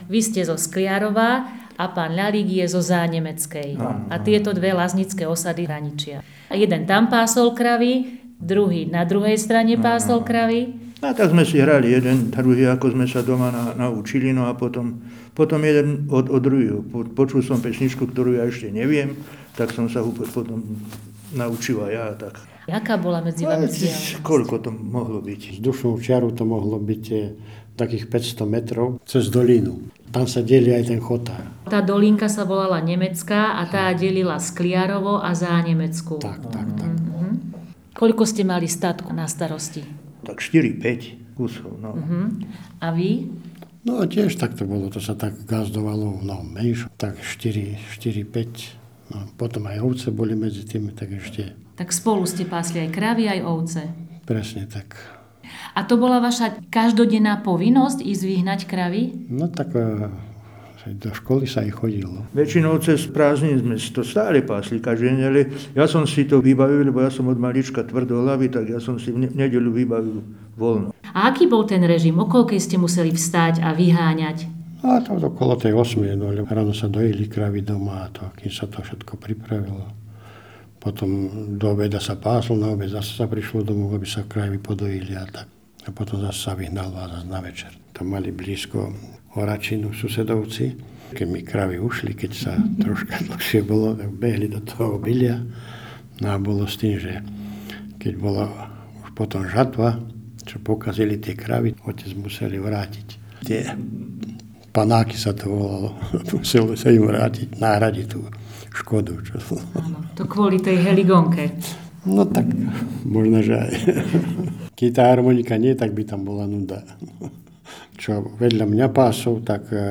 Place of capture Detva